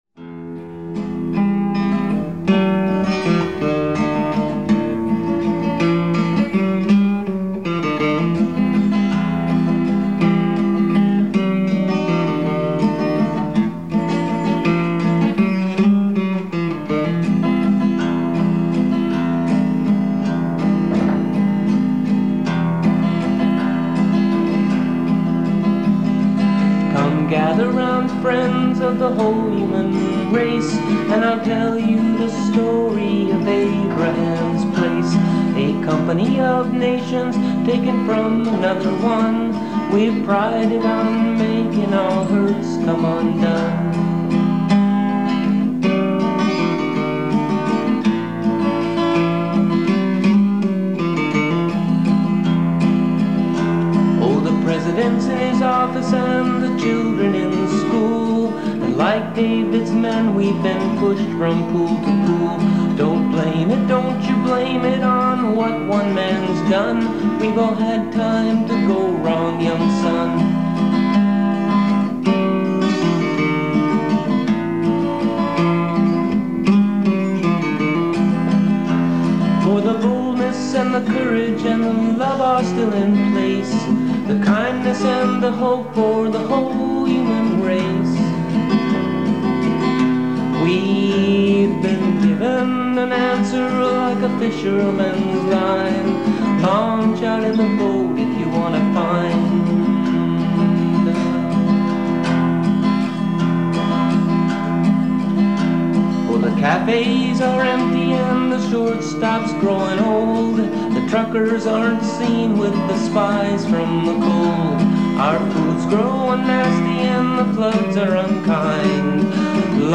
Abrahams-Place-Studio-Version.mp3